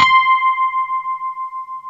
RHODES C5.wav